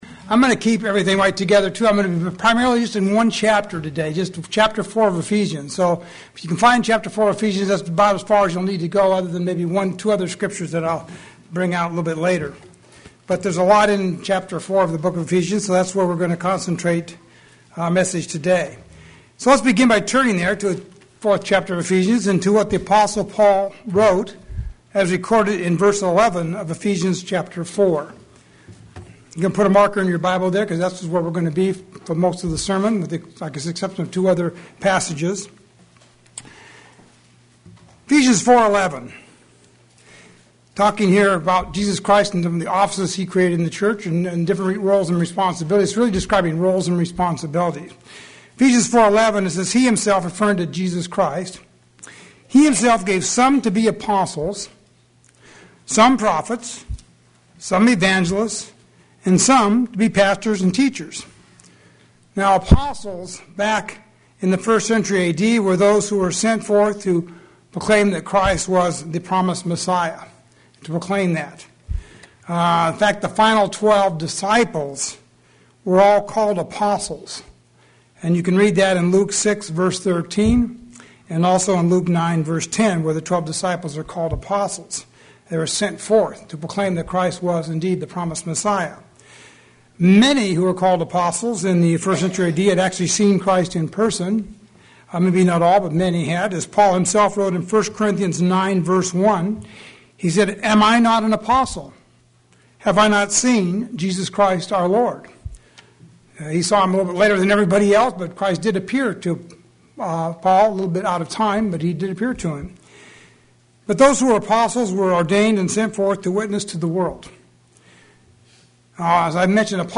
Also, I will show how we can strive to become like Jesus Christ. sermon Transcript This transcript was generated by AI and may contain errors.